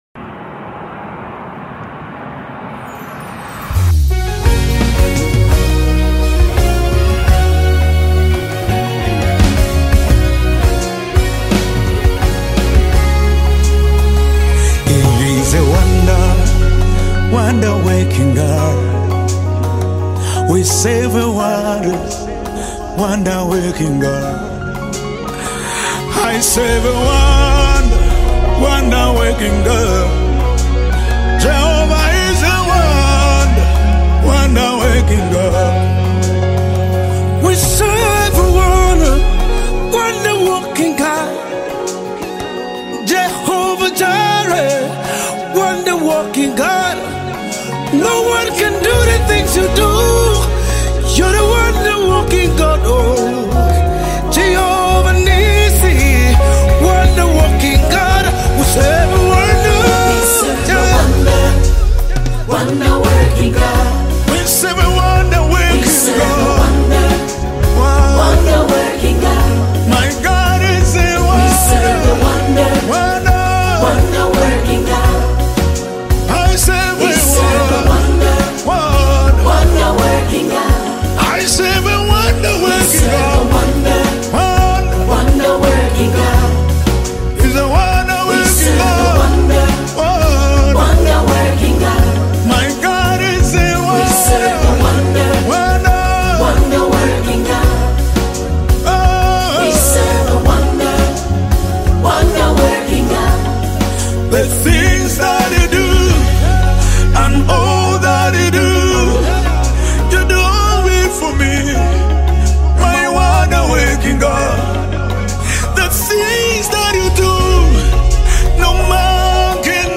Latest Zambian Rumba Gospel Song 2025